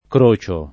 crociare